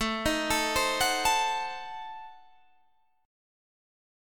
Adim7 chord